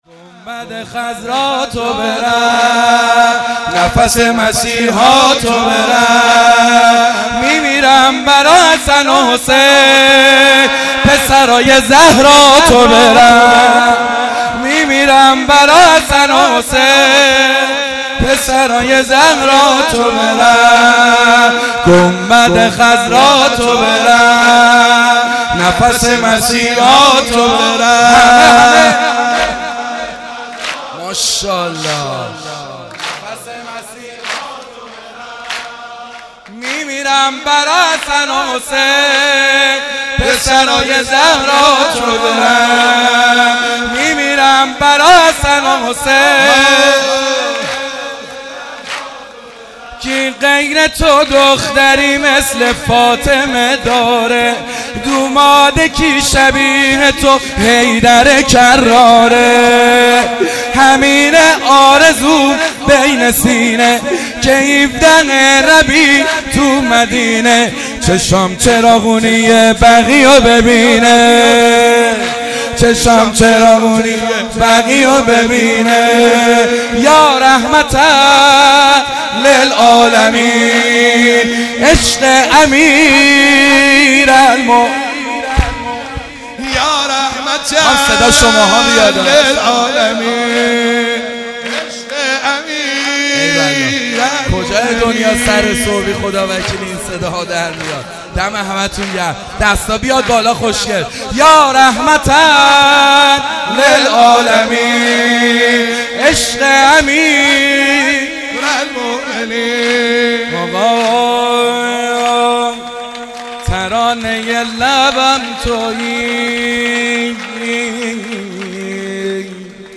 میلاد رسول اکرم(ص) و امام صادق(ع)/هیئت رزمندگان غرب تهران